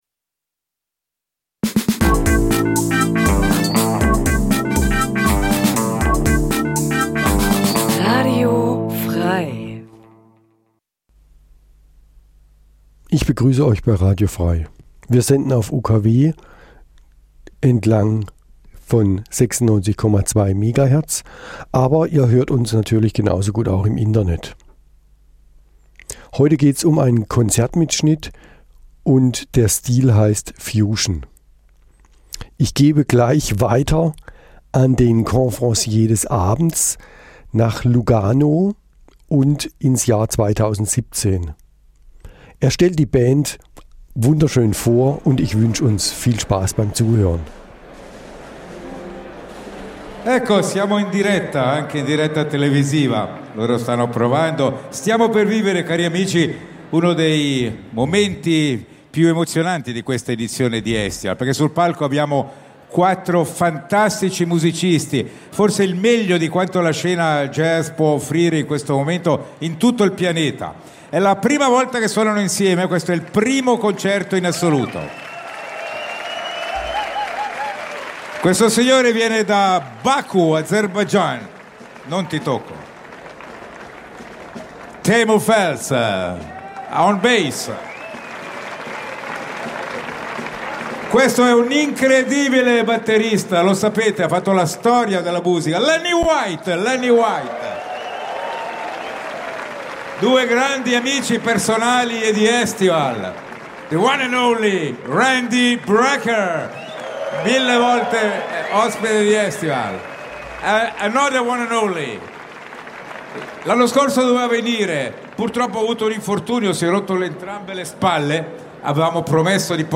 live at EstivalJazzLugano 2017
Eine Stunde Jazz Dein Browser kann kein HTML5-Audio.